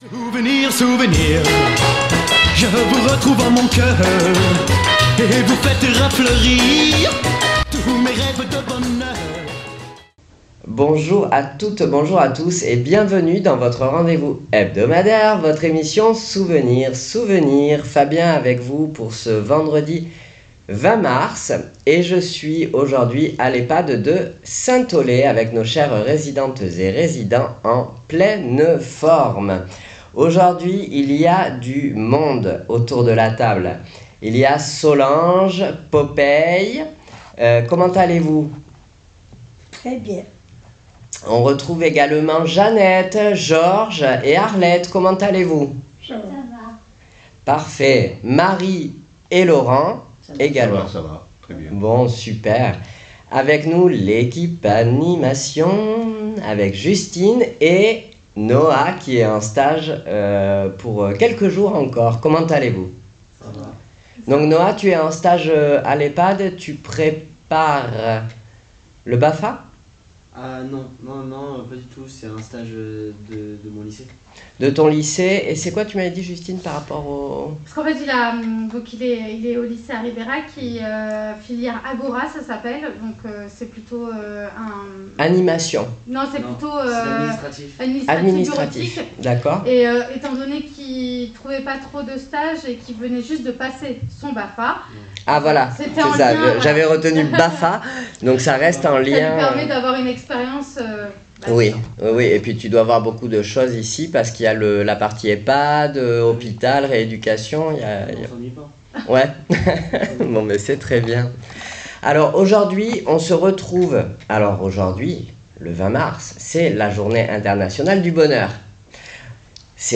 Souvenirs Souvenirs 20.03.26 à l'Ehpad de Saint Aulaye " Le bonehur en fleur "